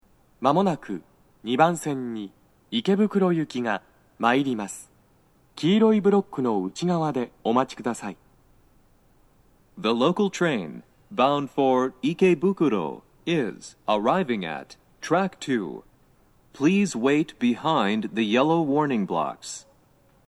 mshinjukugyoemmae2sekkin.mp3